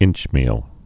(ĭnchmēl)